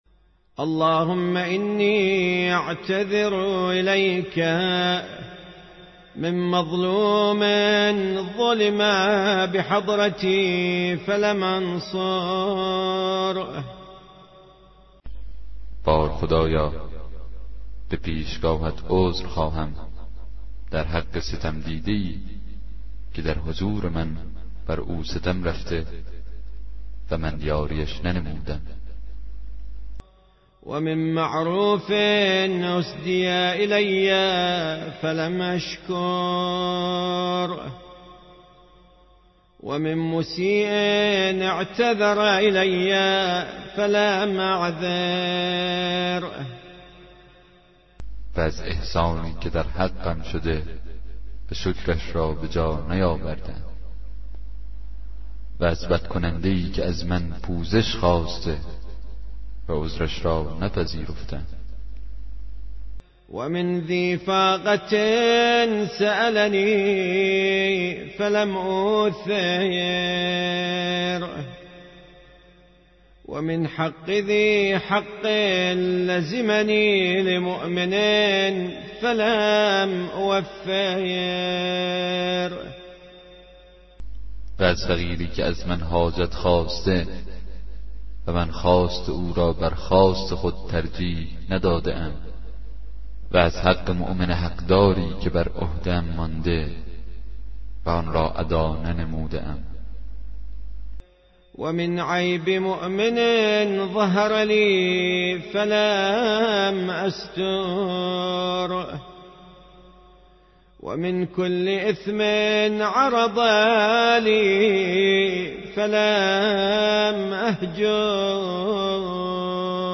کتاب صوتی دعای 38 صحیفه سجادیه